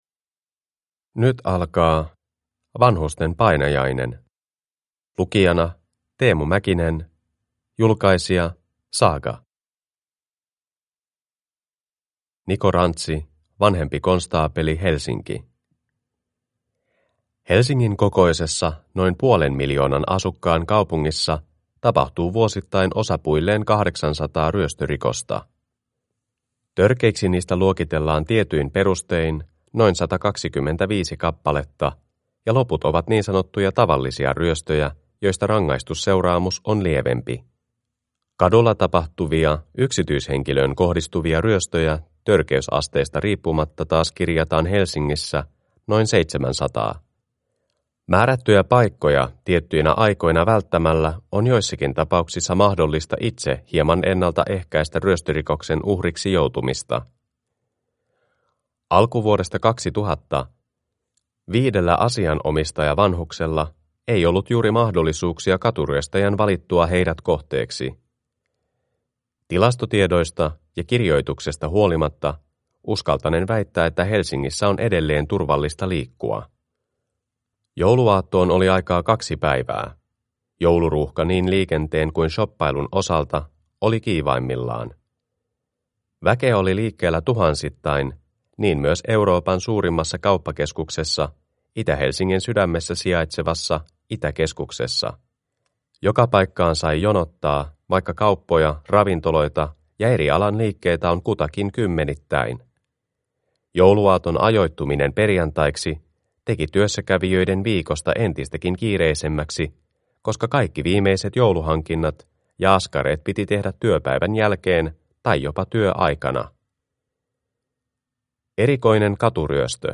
Vanhusten painajainen (ljudbok) av Eri tekijöitä